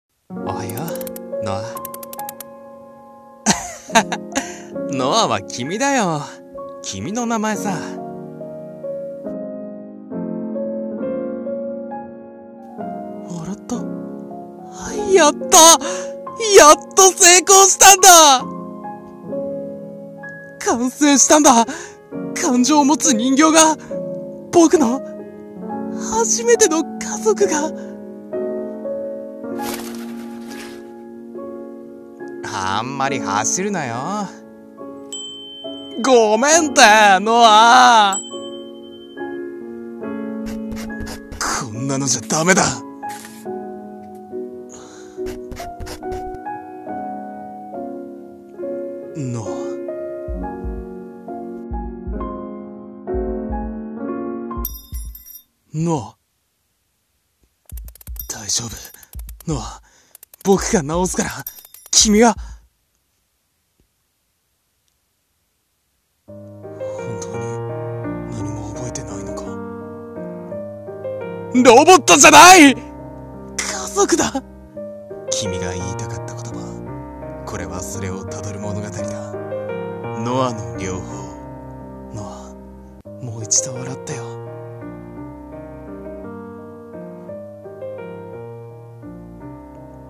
【映画予告風声劇】ノアの療法